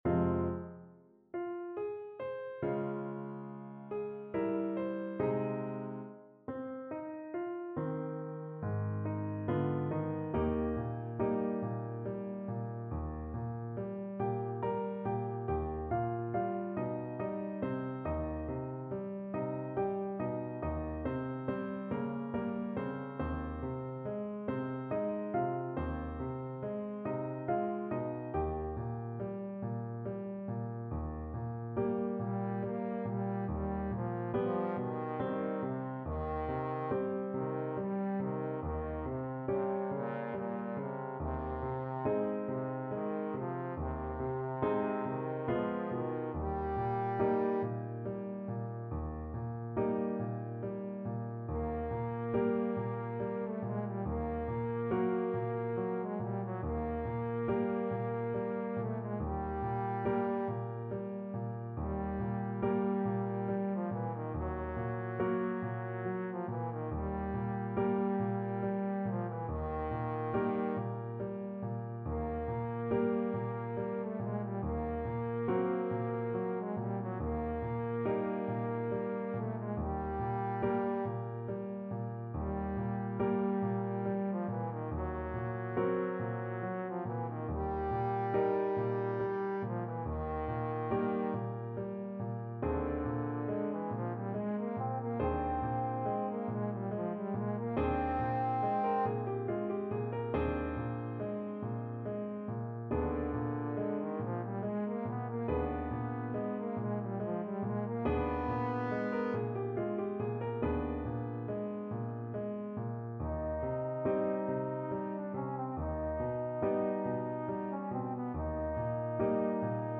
3/4 (View more 3/4 Music)
Bb3-Eb5
Andantino =70 (View more music marked Andantino)
Classical (View more Classical Trombone Music)